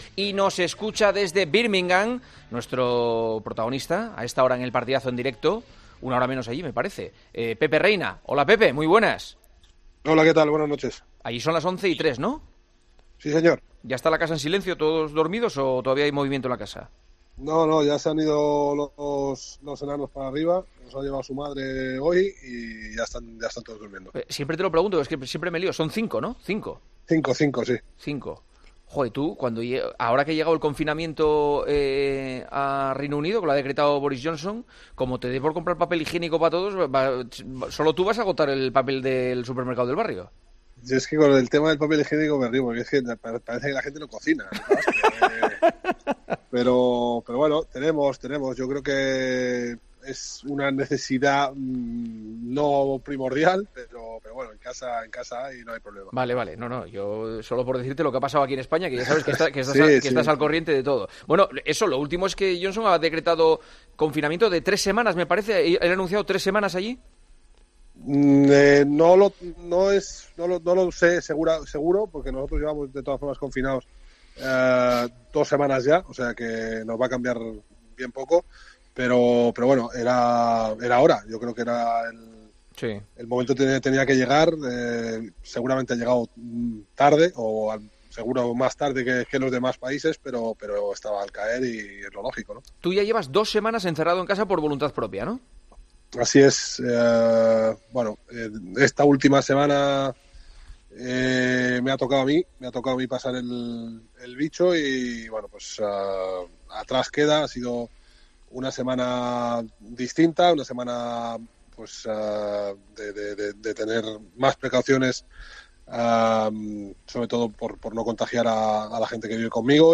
AUDIO: Hablamos con el portero del Aston Villa de la crisis del coronavirus y de cómo lo ha pasado él después de estar infectado.